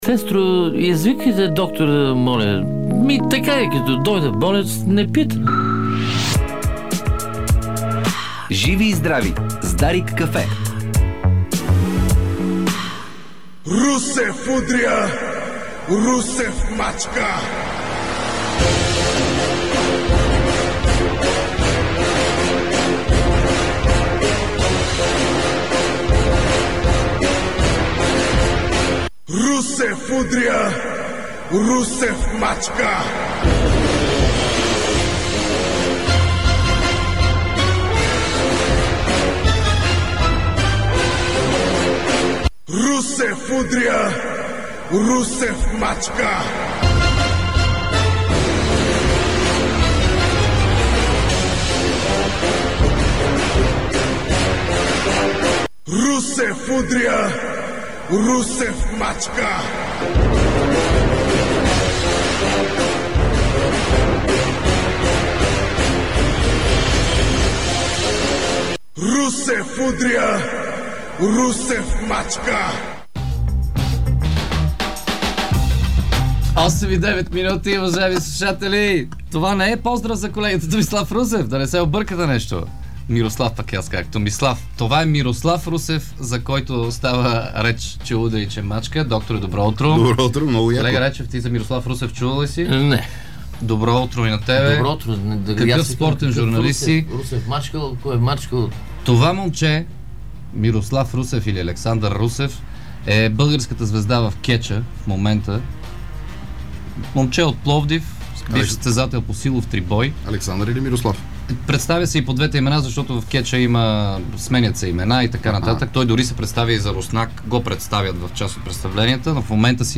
в студиото на Дарик радио